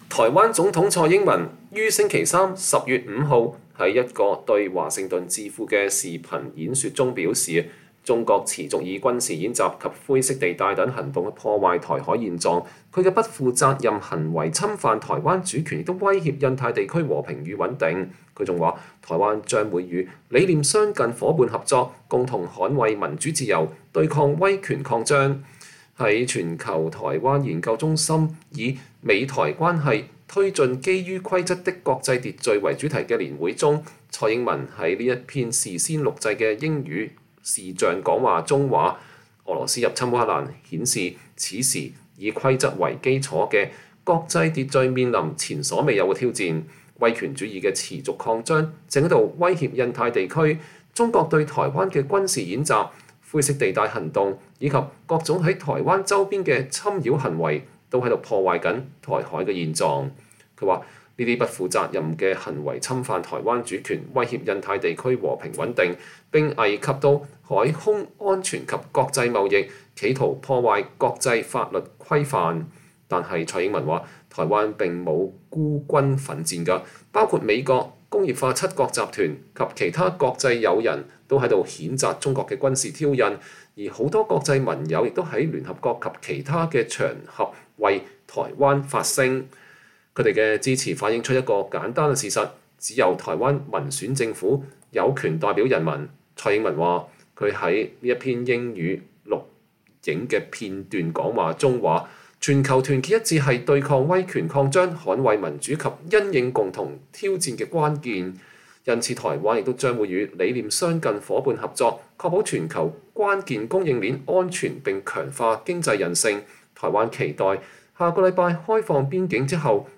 蔡英文對美智庫發表視頻演說：中國破壞台海現狀威脅印太和平穩定